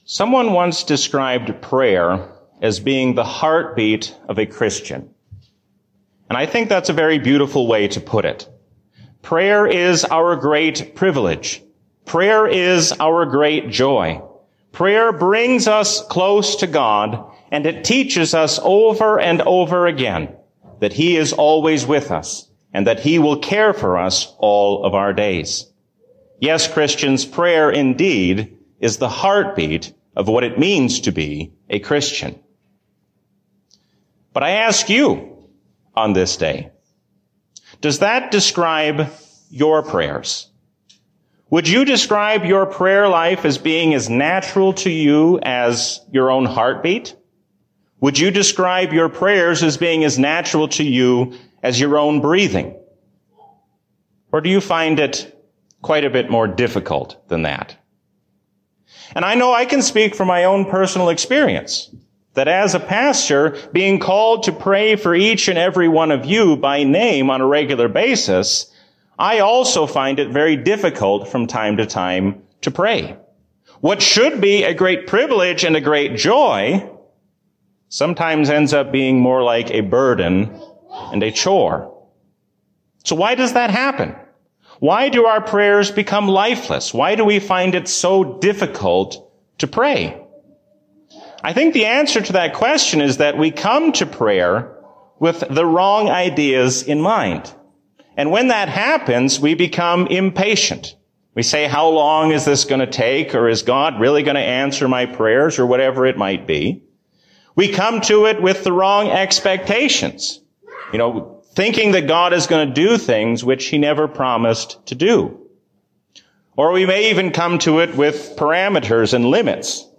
A sermon from the season "Trinity 2022." God is your loving Father and gives you the strength to face all of life's troubles.